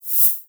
edm-hihat-14.wav